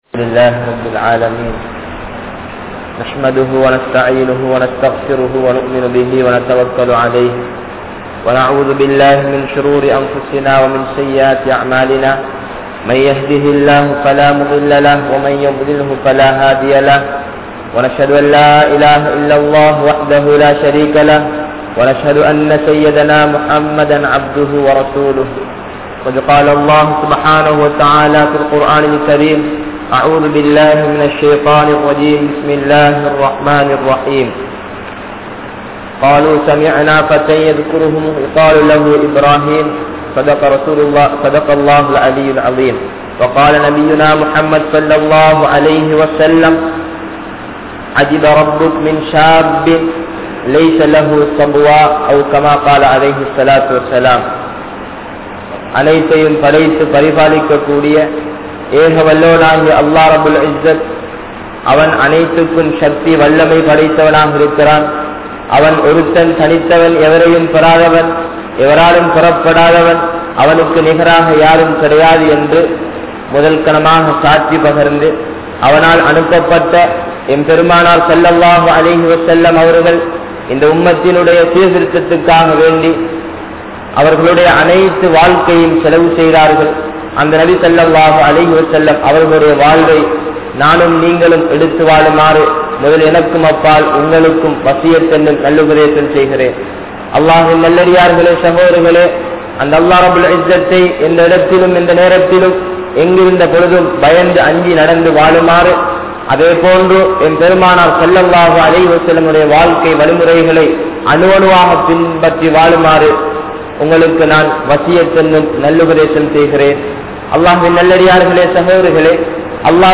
Vaalifaththin Perumathi (வாலிபத்தின் பெறுமதி) | Audio Bayans | All Ceylon Muslim Youth Community | Addalaichenai
South Eastern University Jumua Masjith